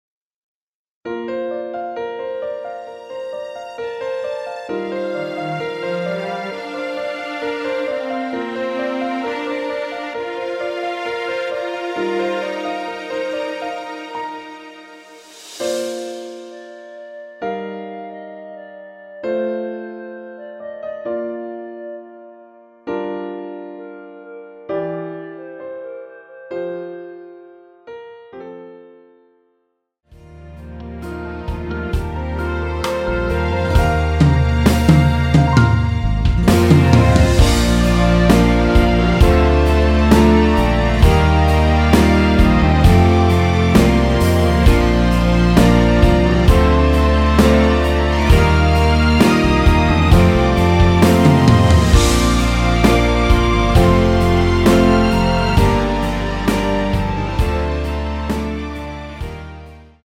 원키 멜로디 포함된 MR입니다.
Bb
앞부분30초, 뒷부분30초씩 편집해서 올려 드리고 있습니다.